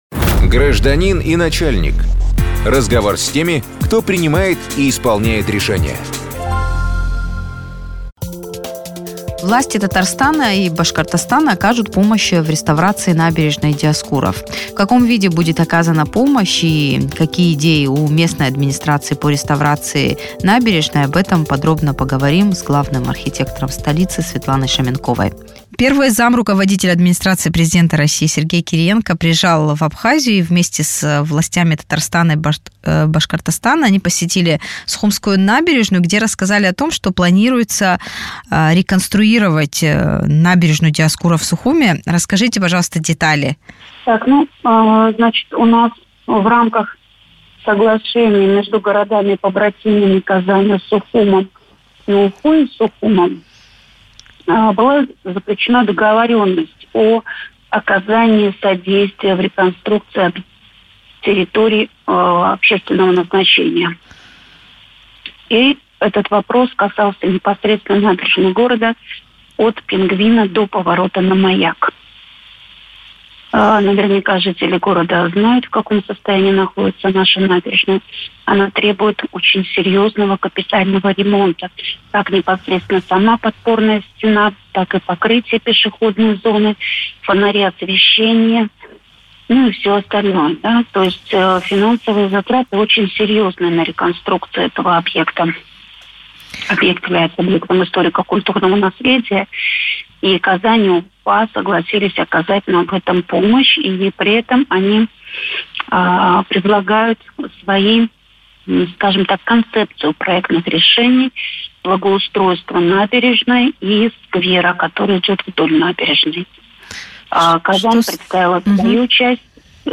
Главный архитектор Сухума Светлана Шаменкова в интервью радио Sputnik рассказала, когда начнутся глобальные ремонтные работы на набережной Диоскуров в столице Абхазии и кто окажет помощь в реализации проекта.